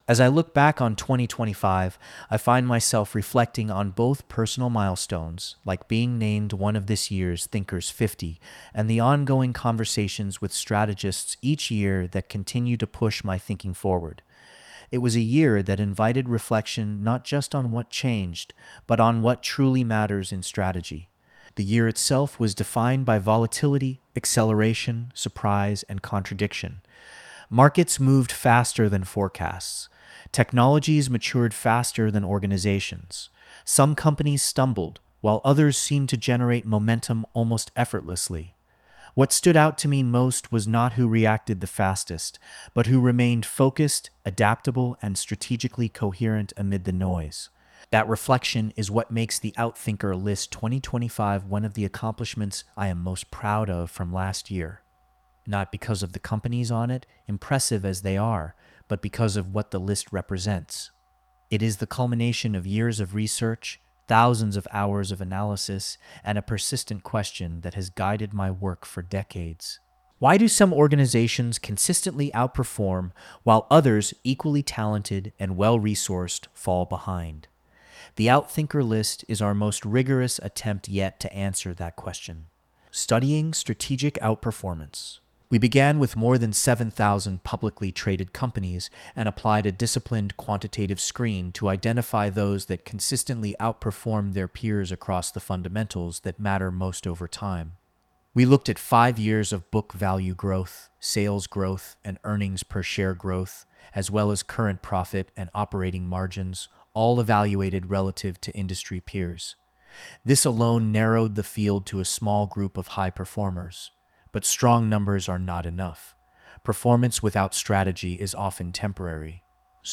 This audio was recorded by AI: